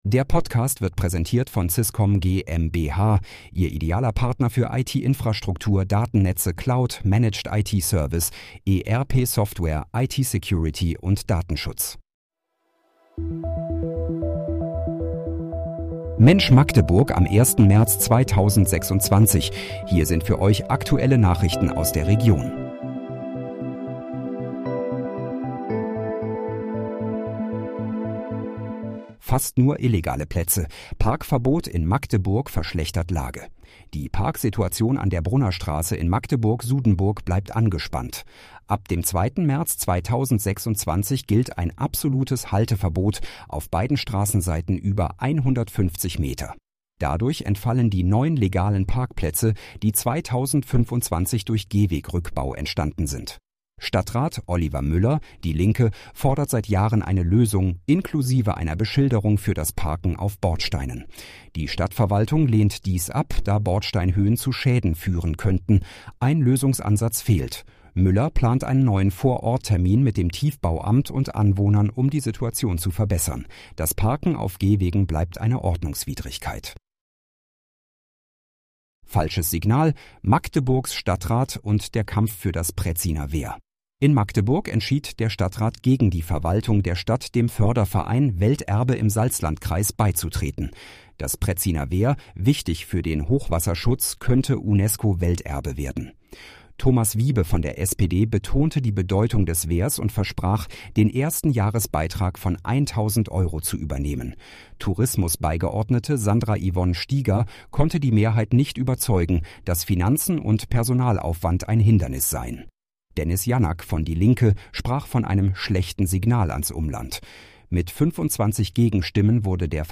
Mensch, Magdeburg: Aktuelle Nachrichten vom 01.03.2026, erstellt mit KI-Unterstützung